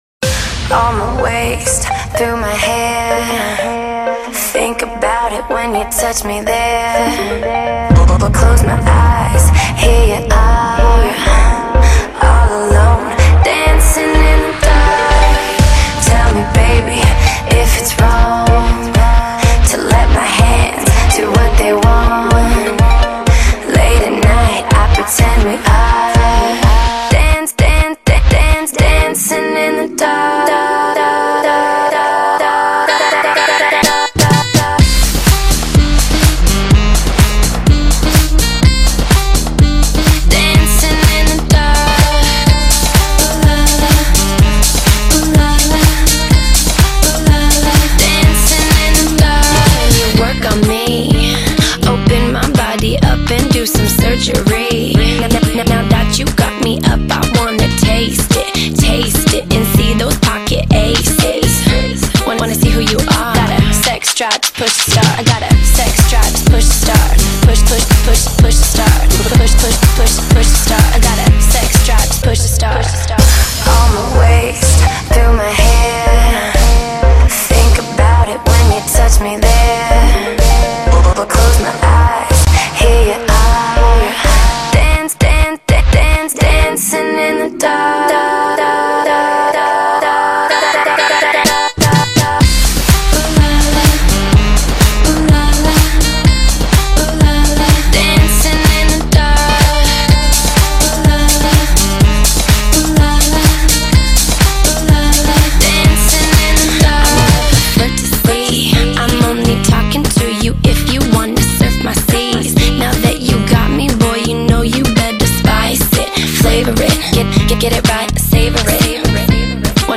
американская певица.